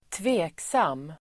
Uttal: [²tv'e:ksam:]